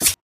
HitSlice.ogg